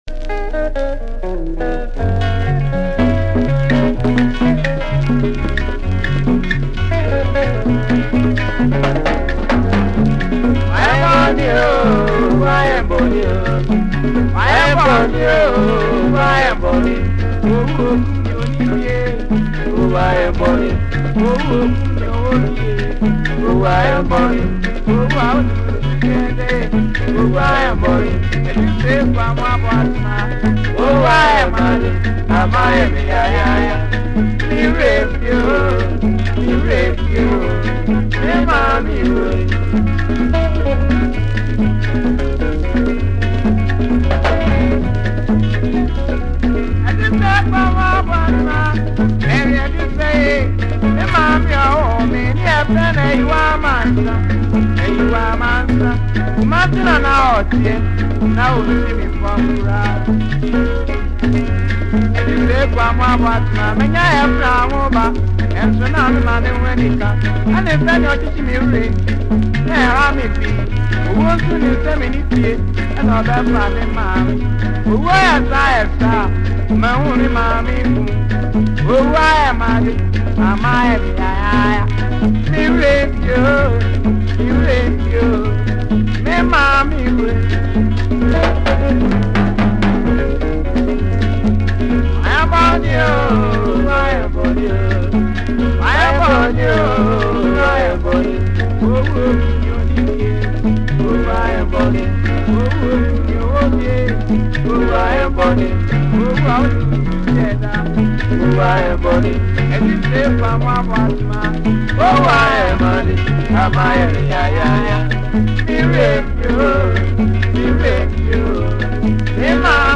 an old classical song